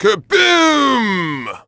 Here are a couple of bonus sound effects that ALWAYS make me laugh:
KerBoom
WW_Salvatore_Kerboom.wav